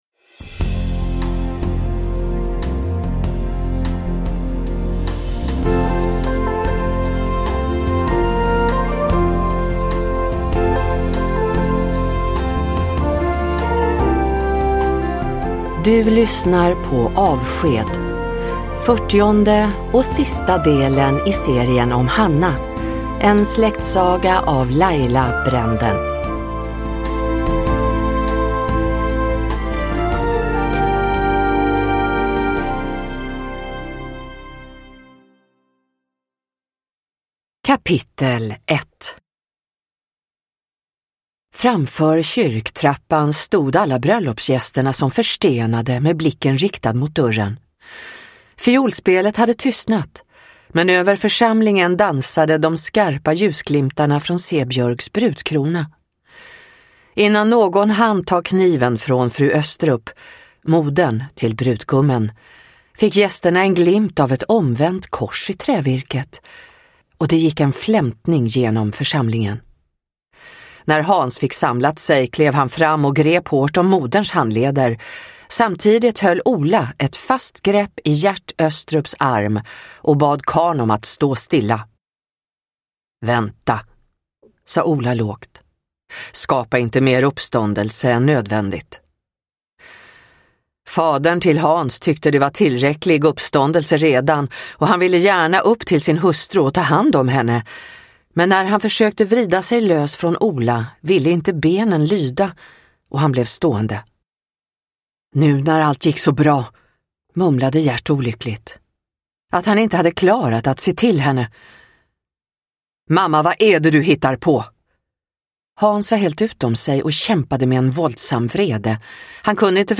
Avsked – Ljudbok – Laddas ner